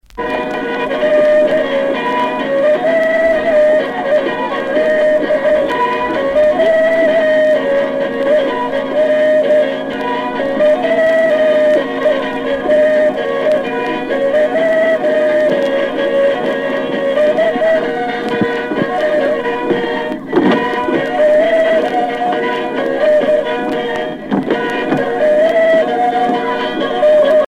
danse
Sonneurs de vielle traditionnels en Bretagne
Pièce musicale éditée